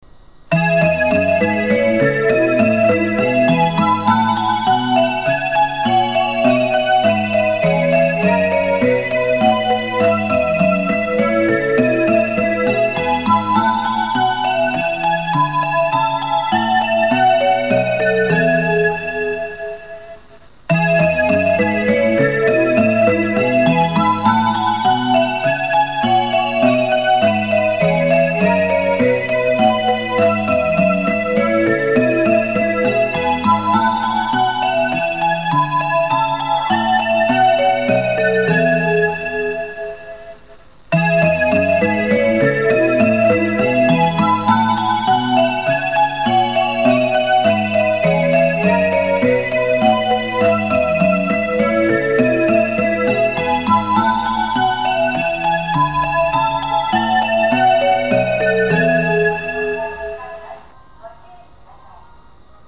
メロディは一度スイッチを押せば自動的に３ターン流れますが、信号開通のタイミングによっては途中で切られる場合があります。
朝７時以降はほぼ確実に駅員放送がメロディに被ります。
−現在の放送−
発車メロディ